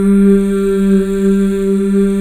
Index of /90_sSampleCDs/Club-50 - Foundations Roland/VOX_xFemale Ooz/VOX_xFm Ooz 2 S